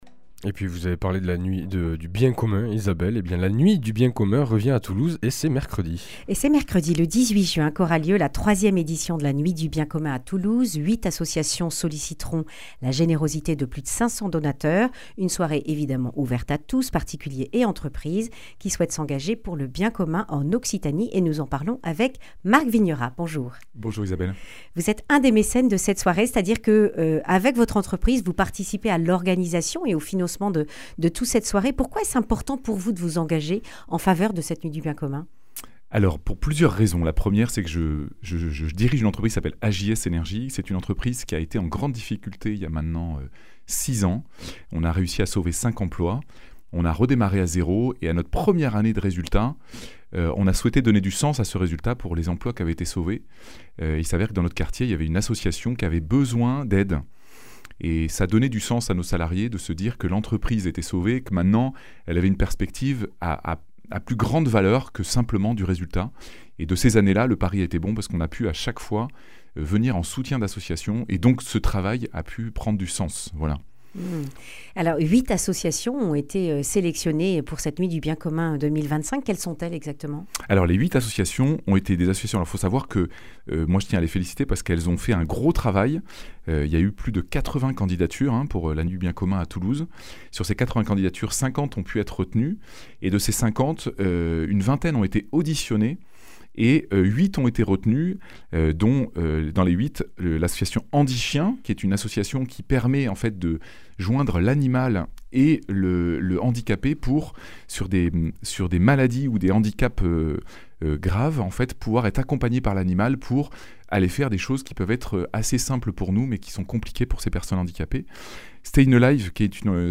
lundi 16 juin 2025 Le grand entretien Durée 10 min